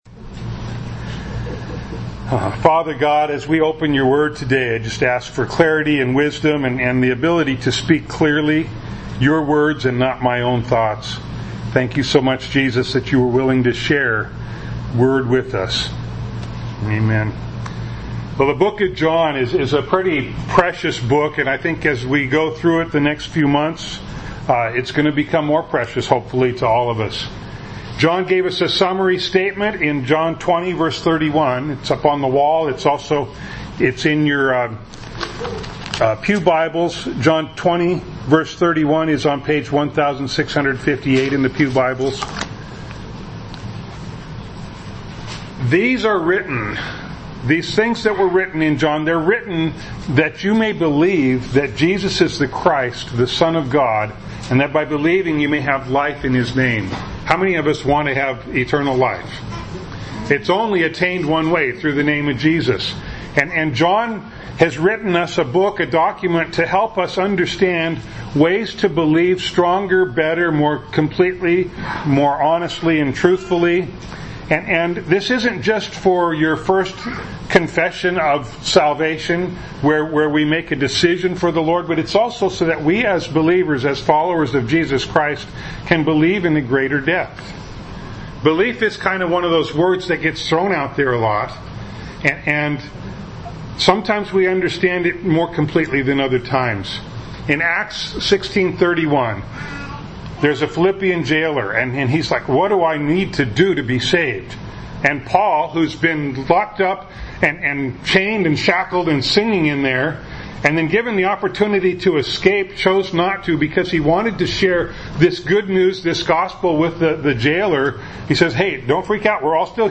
John 1:19-34 Service Type: Sunday Morning Bible Text